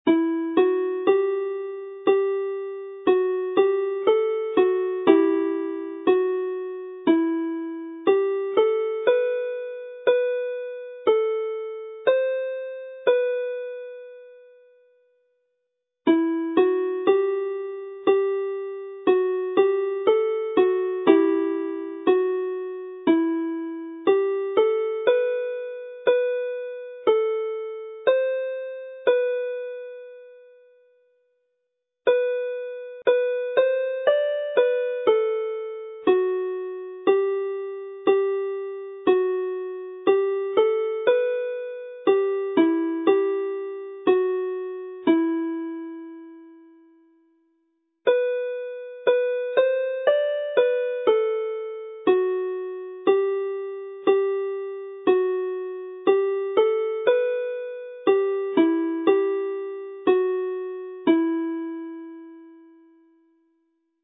Alawon Cymreig - Set Glan Camlad - Welsh folk tunes to play
Glan Camlad (Bank of the Camlad) is a simple but deep and moving Welsh melody, well suited to the harp, with the Am chord in the first phrase creating a haunting harmony.